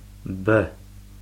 b audio speaker icon
бэ bat